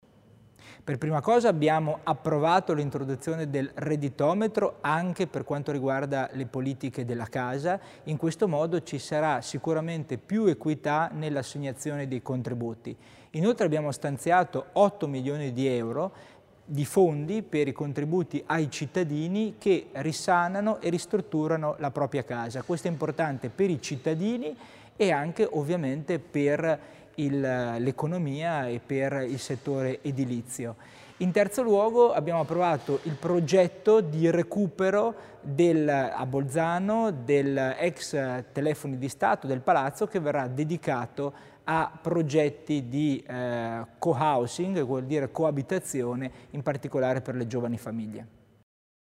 L'Assessore Tommasini spiega le novità nel settore edilizio